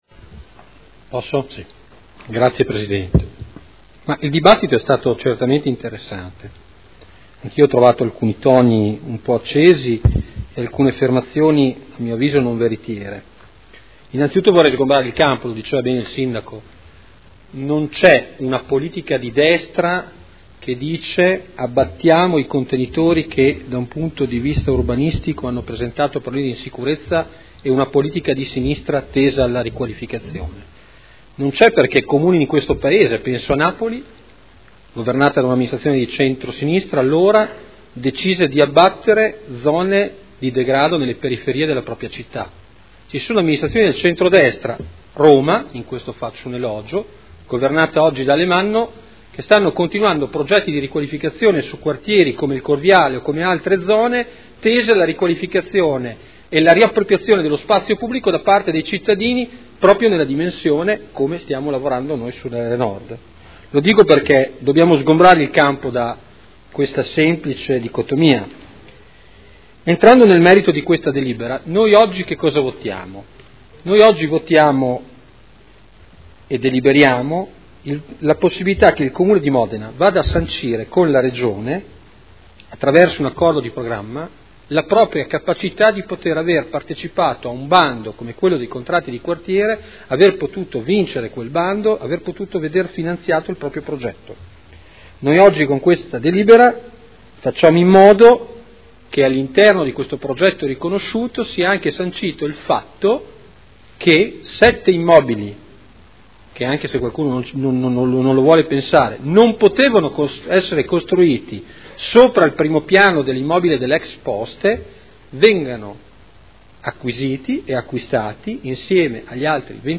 Seduta del 25/06/2012.